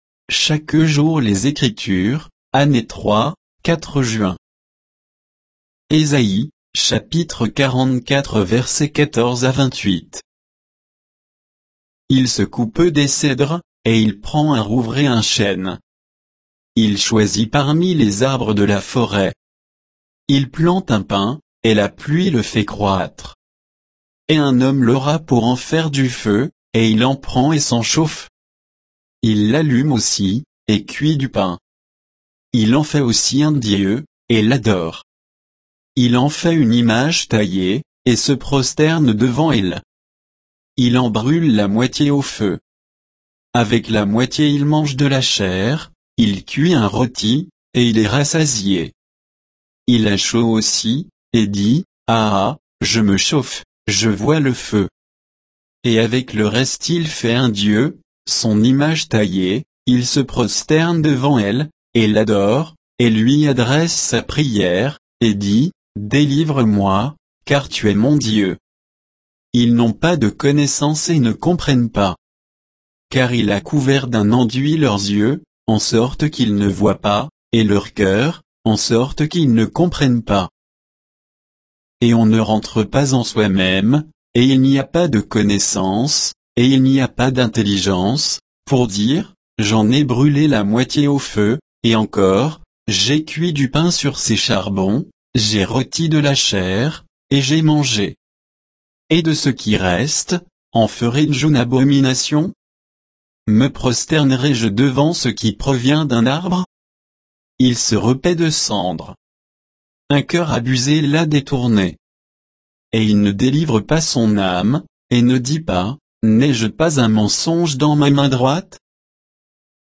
Méditation quoditienne de Chaque jour les Écritures sur Ésaïe 44, 14 à 28